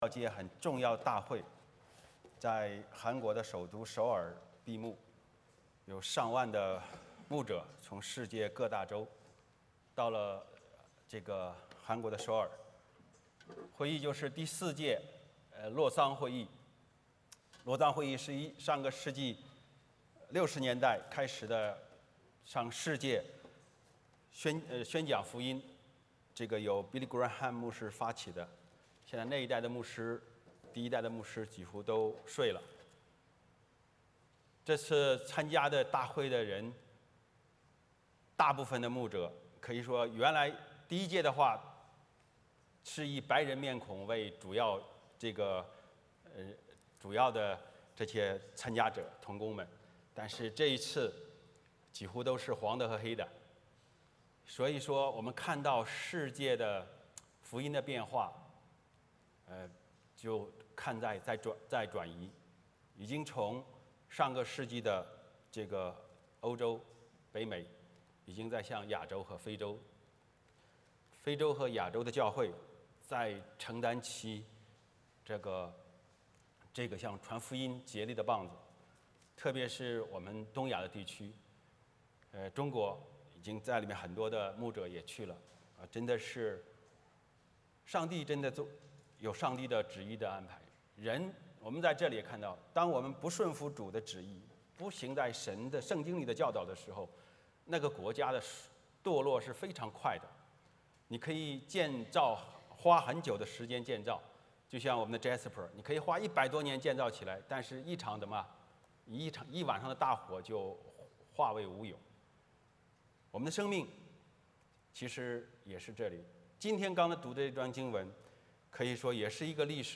使徒行传 14:20b-15:2 Service Type: 主日崇拜 欢迎大家加入我们的敬拜。